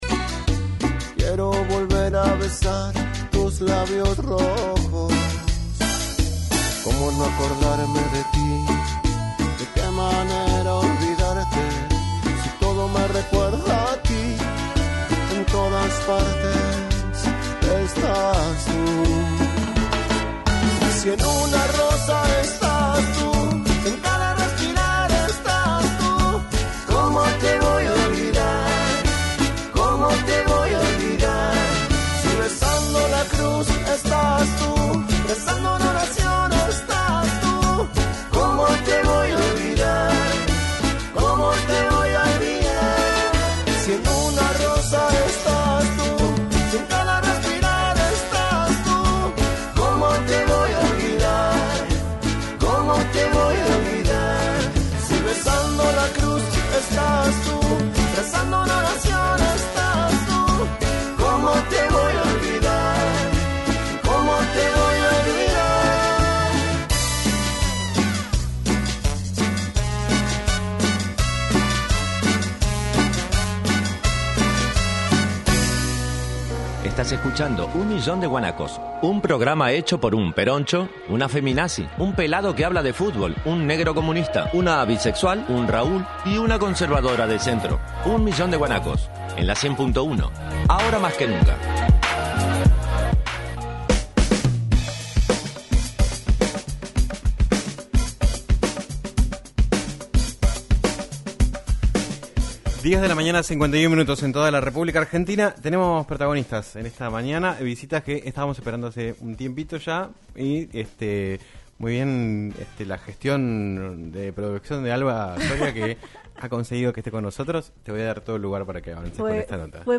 María Magdalena Cativa, titular de la Secretaría de la Mujer, Género, Juventud y Diversidad de Comodoro Rivadavia, visitó los estudios de LaCienPuntoUno para hablar en “Un Millón de Guanacos” sobre este nuevo rol que le tocó asumir semanas atrás, las políticas de género que planea llevar adelante y las dificultades que se presentan en el contexto social que atraviesa el país con un gobierno nacional ausente.